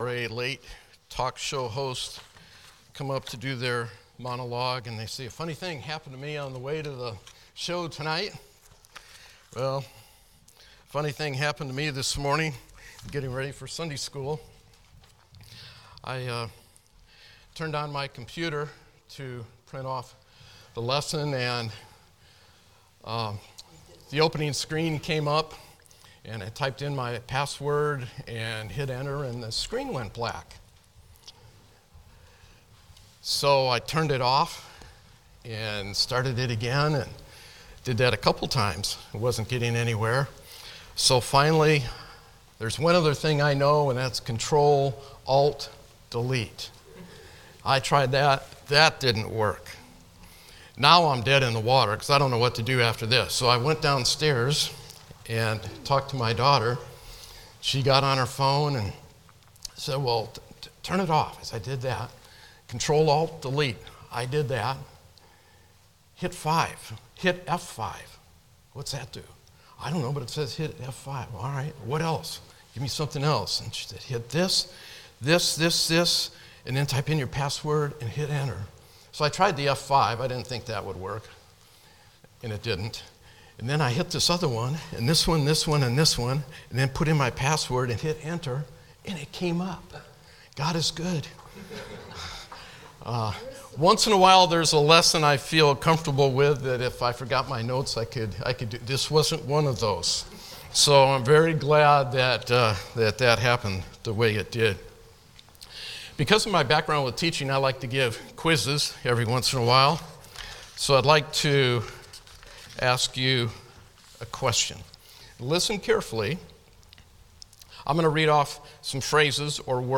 Passage: Revelation 17 Service Type: Sunday School